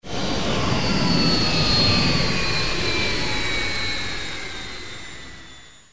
powerdown.wav